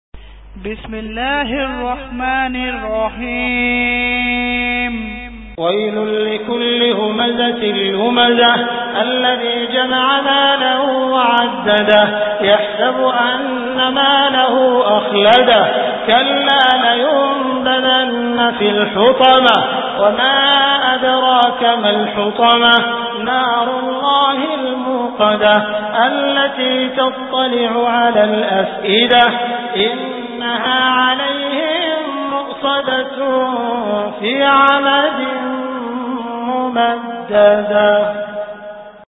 Surah Al Humazah Beautiful Recitation MP3 Download By Abdul Rahman Al Sudais in best audio quality.